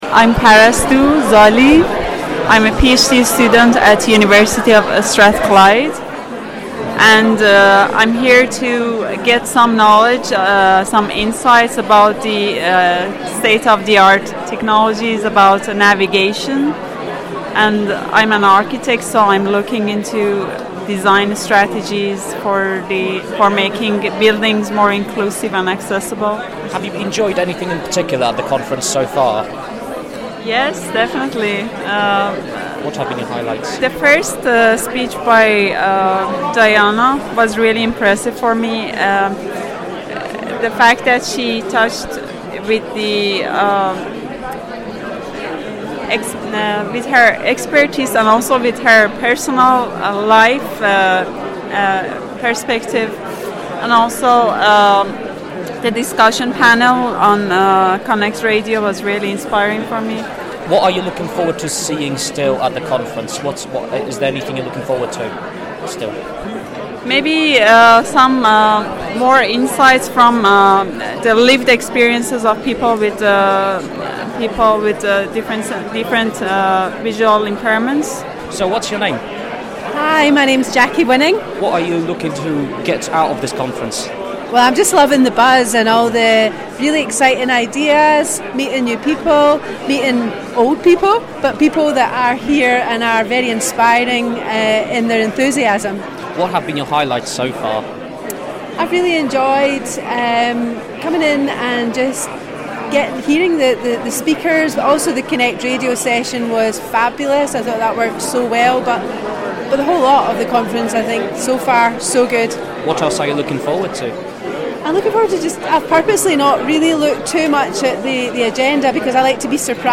Vox Pops From Inclusive Design for Sustainability Conference 2023